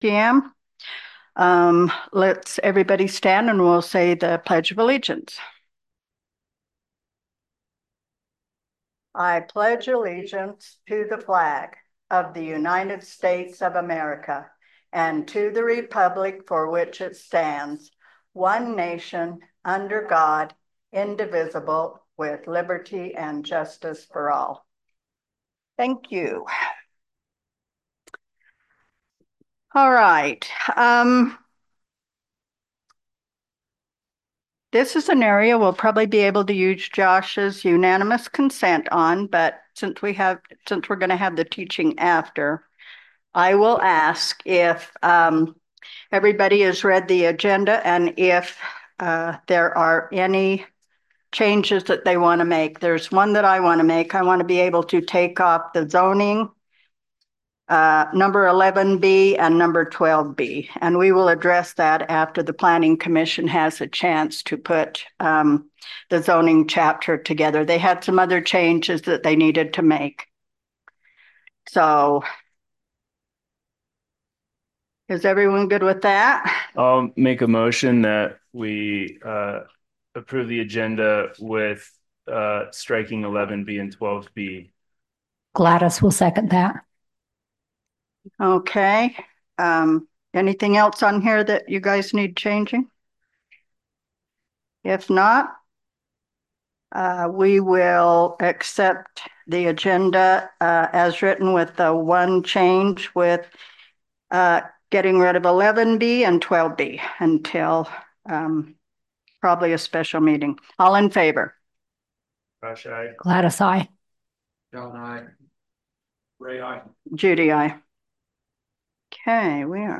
Town Council, Regular Meeting and Public Hearing, Thursday, November 7, 2024, 7pm | Boulder, Utah
Boulder Town Council will hold a public hearing at their regular meeting on Thursday November 7, 2024, (Regular meeting starts at 7:00 PM.)